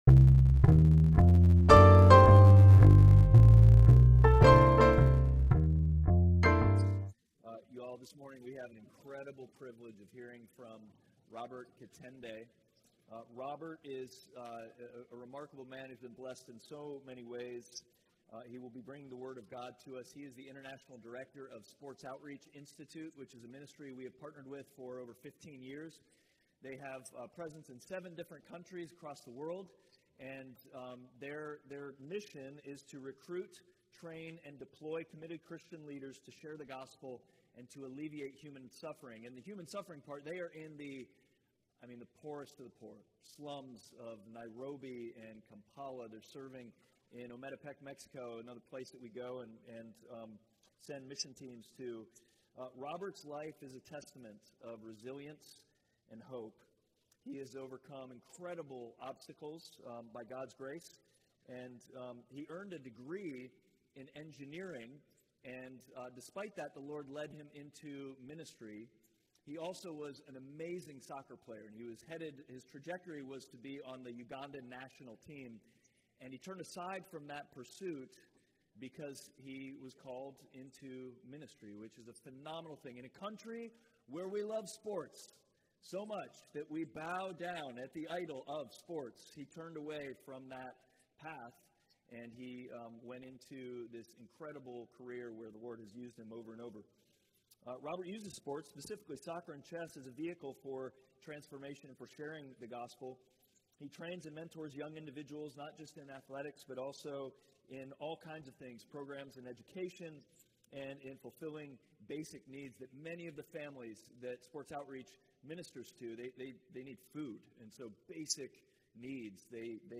Passage: Romans 8:12-17 Service Type: Sunday Worship